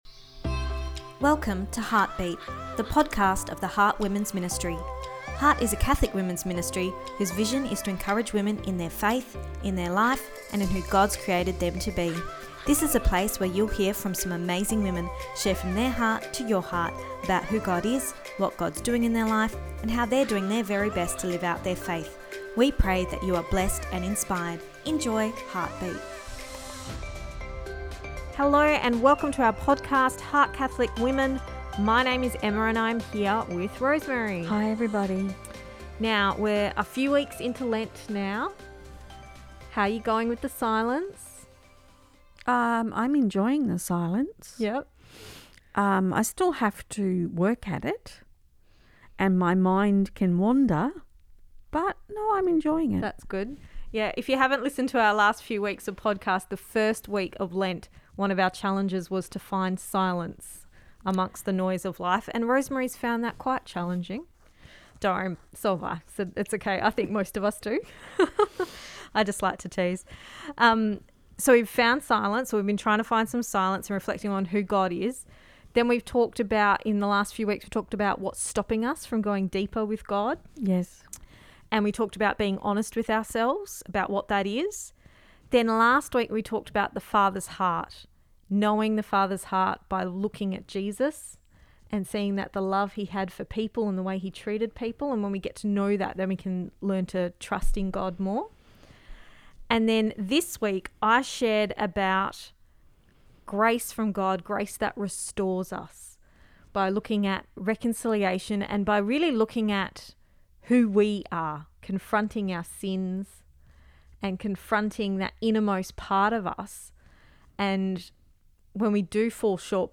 Episode 88 – Grace that Restores (Part 2 – Our Chat)